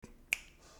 Snap Soundboard: Play Instant Sound Effect Button